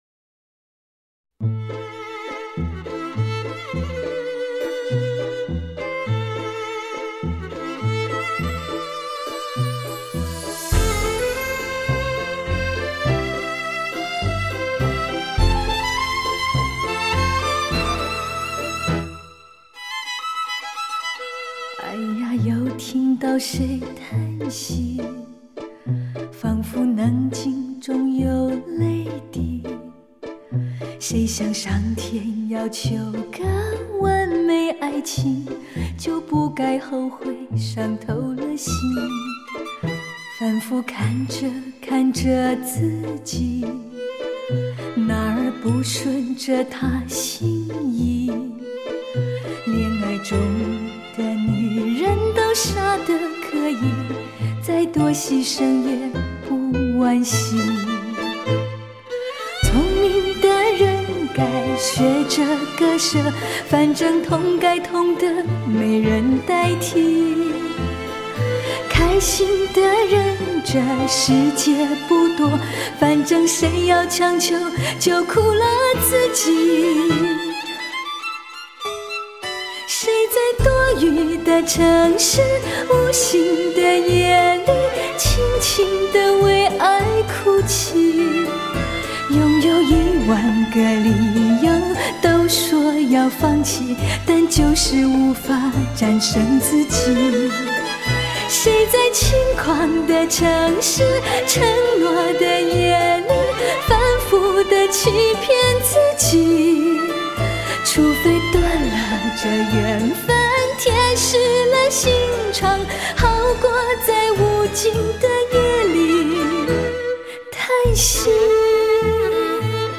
世纪末最惊心动魄的女人情歌